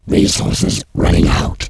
alien_lowresources.wav